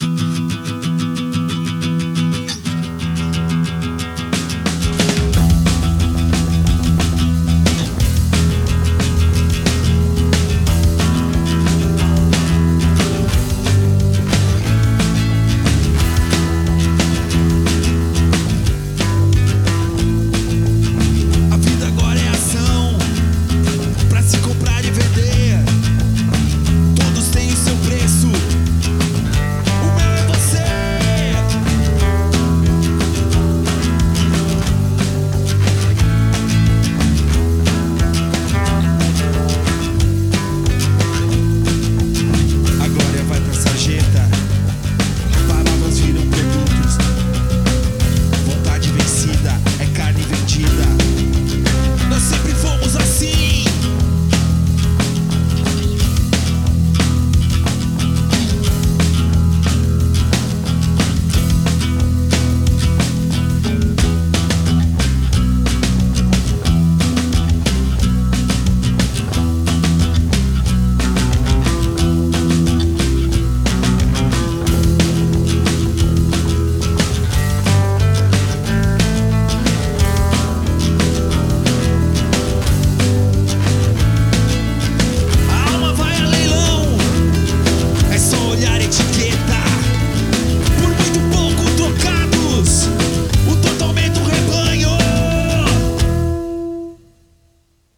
Equipment used: old nylon accoustic guitar cheap iron strings eletro-accoustic guitar Aria Pro Bass...
View attachment REKKED_FULL_mix.mp3 Equipment used: old nylon accoustic guitar cheap iron strings eletro-accoustic guitar Aria Pro Bass Reaper Some beer to an old singer Any comments and analisys welcome.